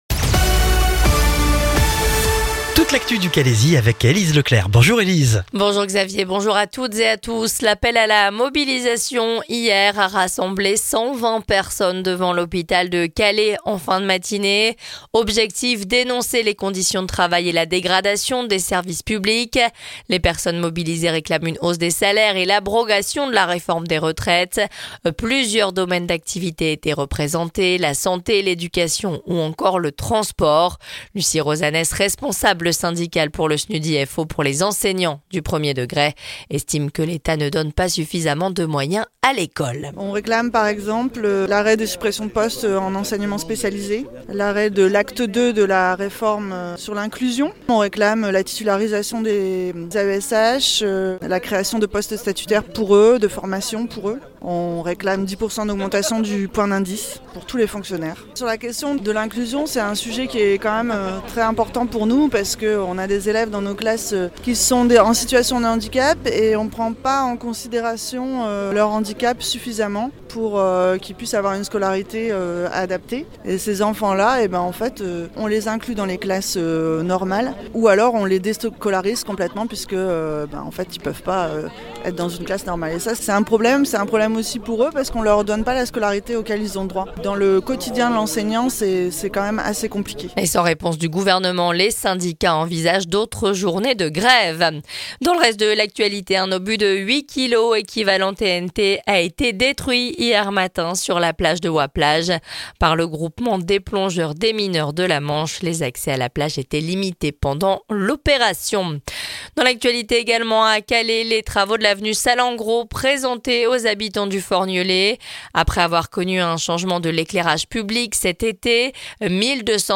Le journal du mercredi 2 octobre dans le Calaisis